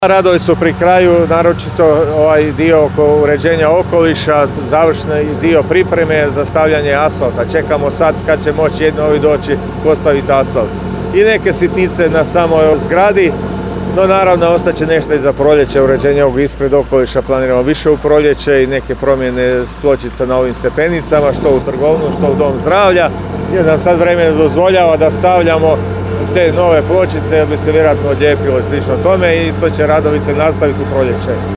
Ispred zgrade radi se punom parom i kako ističe načelnik općine Končanica Zlatko Bakunić: